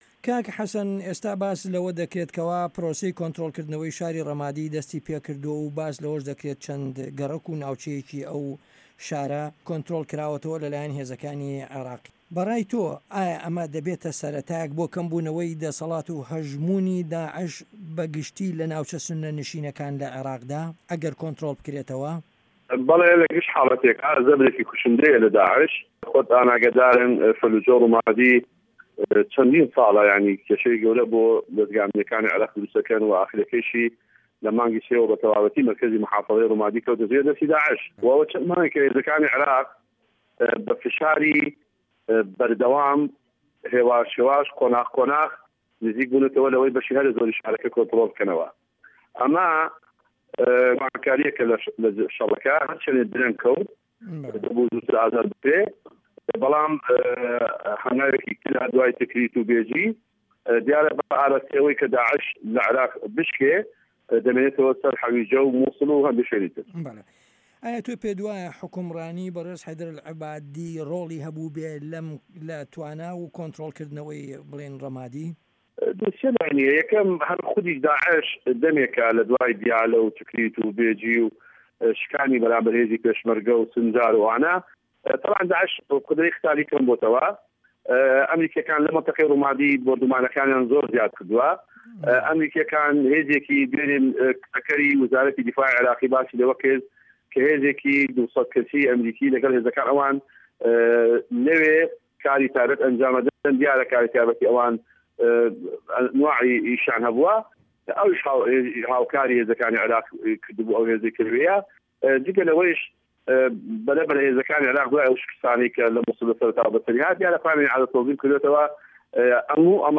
وتوێژ لەگەڵ حەسەن جیهاد